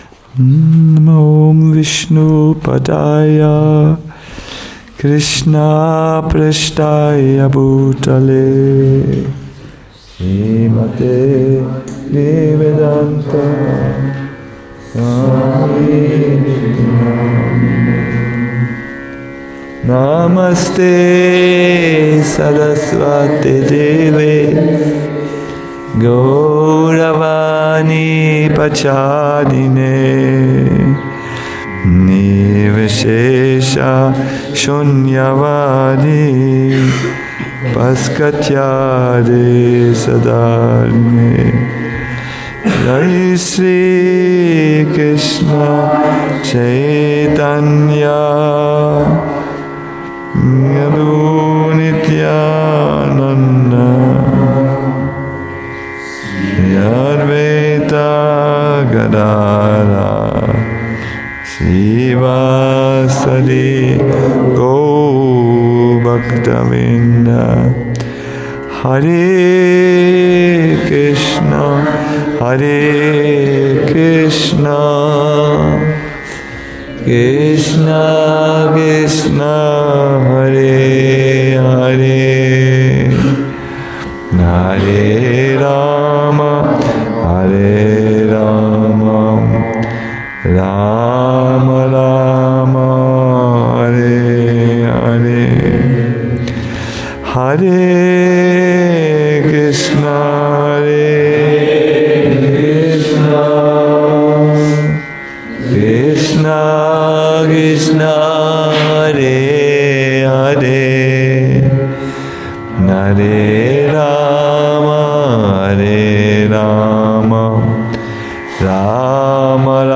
a lecture
Vedic Academy